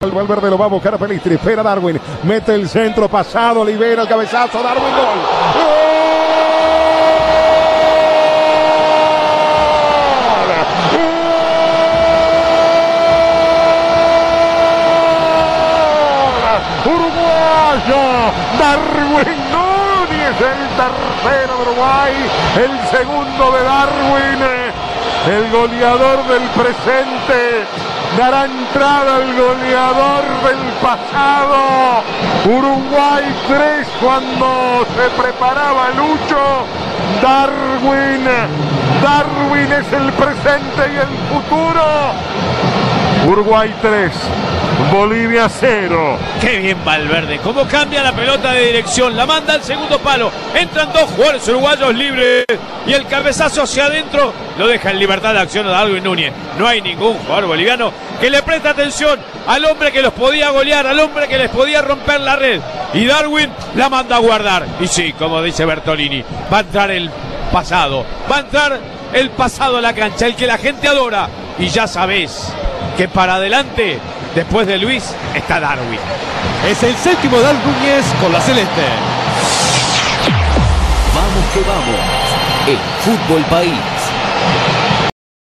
La victoria celeste en la voz del equipo de Vamos que Vamos